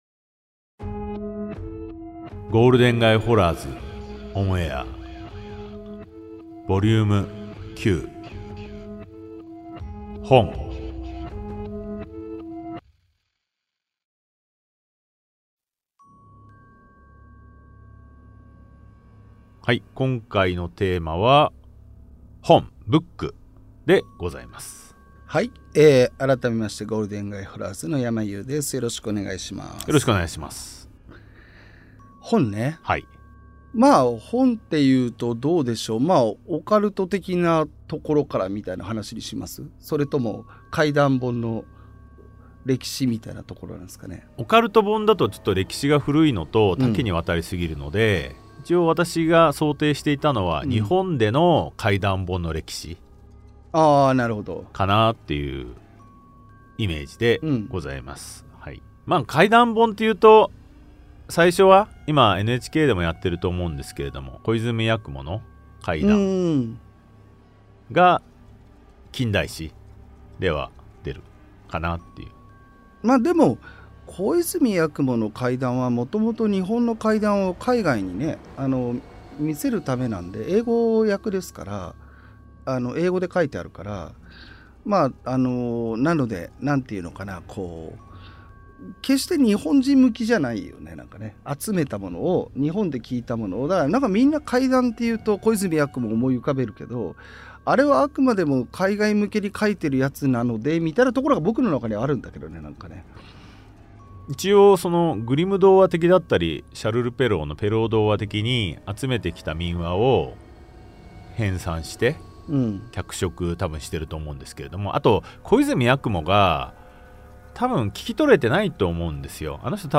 [オーディオブック] ゴールデン街ホラーズ ON AIR vol.09 本
対談形式のホラー番組。